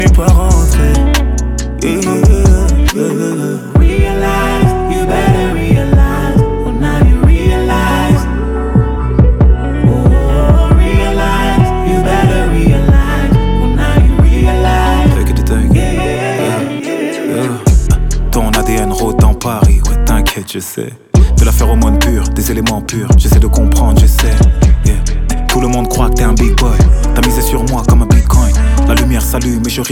Жанр: Фанк / Русские